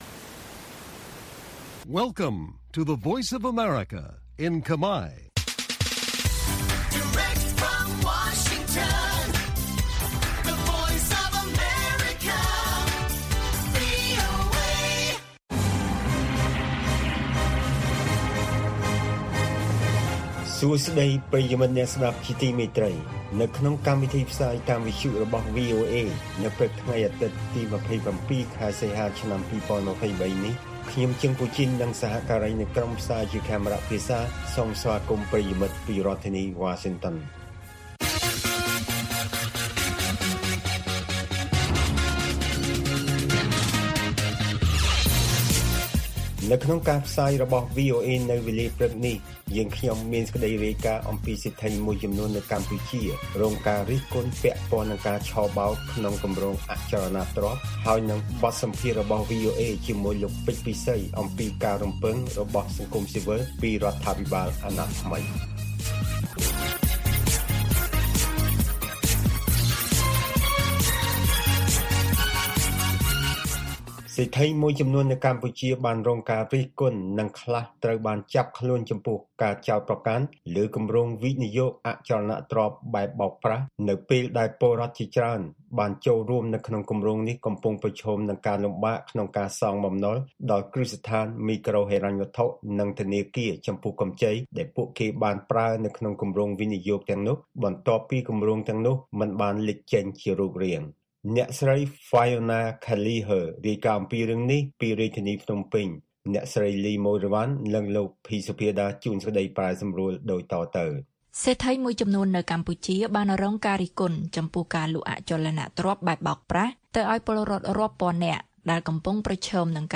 ព័ត៌មាននៅថ្ងៃនេះមានដូចជា សេដ្ឋីមួយចំនួននៅកម្ពុជារងការរិះគន់ពាក់ព័ន្ធនឹងការឆបោកក្នុងគម្រោងអចលនទ្រព្យ។ បទសម្ភាសន៍ VOA